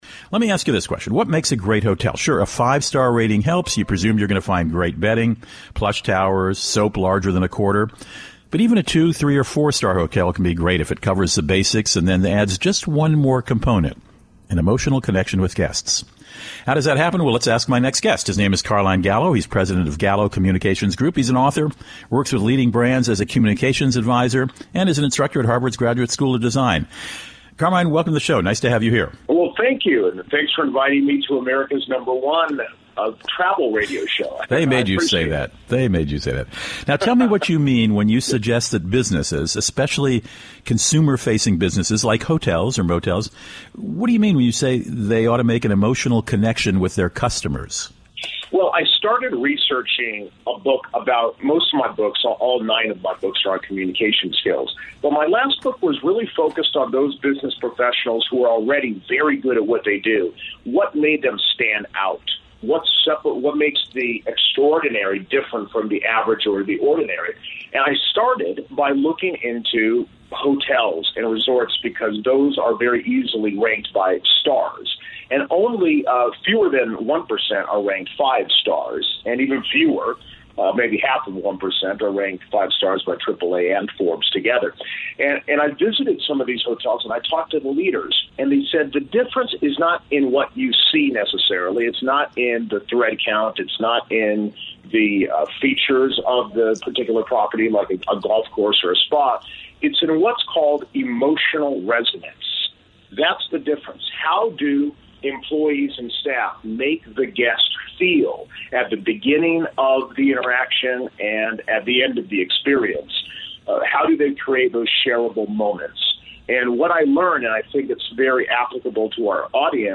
RM Travel World, a leading travel radio show on 415 stations, interviewed Carmine about his book, “Five Stars.”